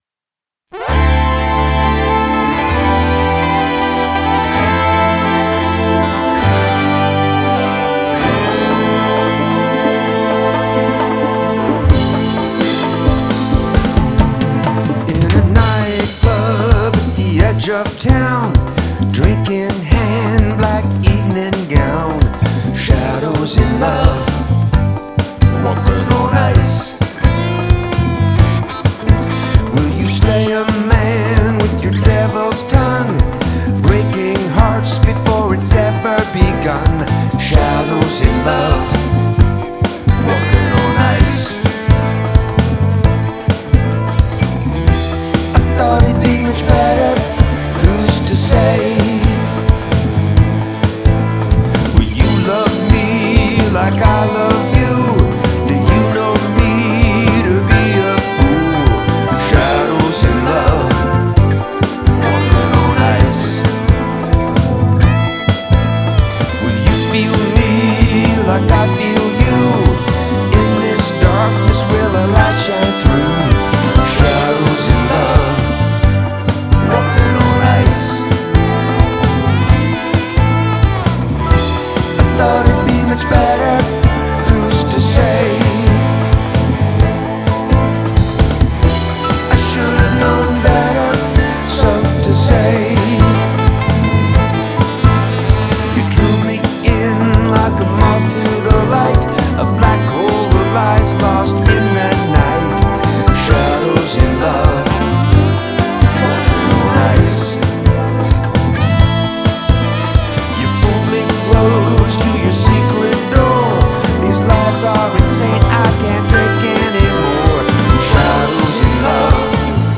Keys
Drums; guitar
Lead and backup vocals
Alto sax